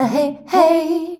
AHEHEY  C.wav